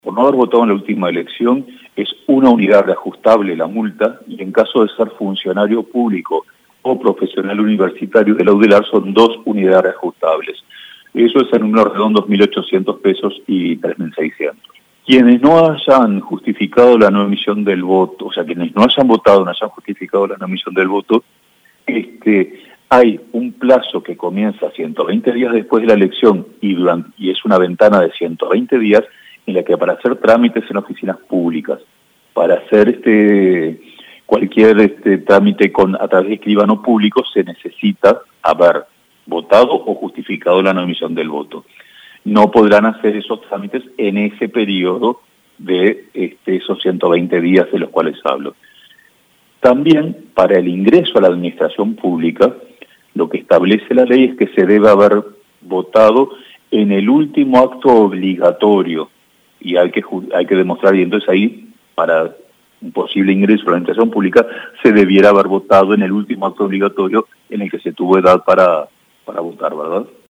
El ministro de la Corte Electoral, Pablo Klappenbach, explicó que sucede con quienes no votaron y no justificaron la emisión del voto.